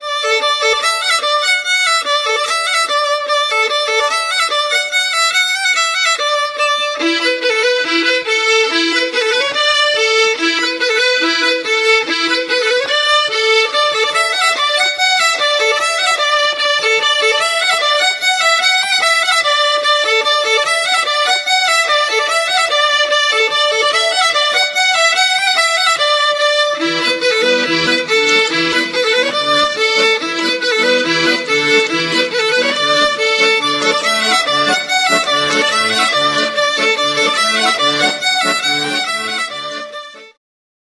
skrzypce
akordeon, flety proste, whistles, cytra
mandolina, gitara akustyczna